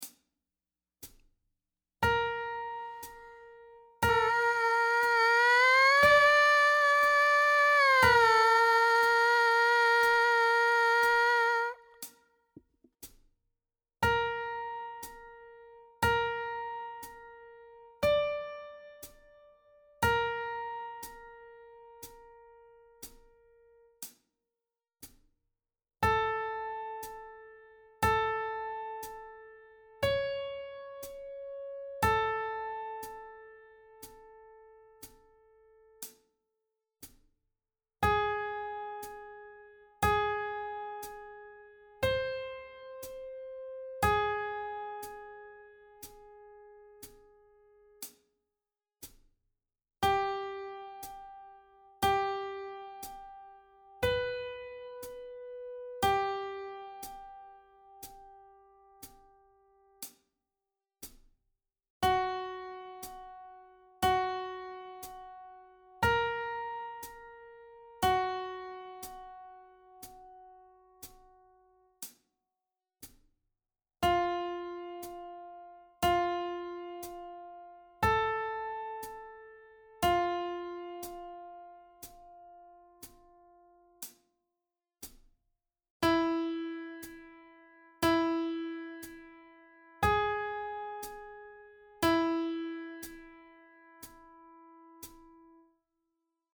Mouthpiece Buzzing #2 play along